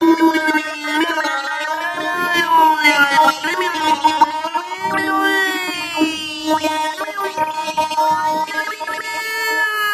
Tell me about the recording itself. Tags: Science/Nature Sounds Recorded in Space Sci-Fi Skylab Sputnik Program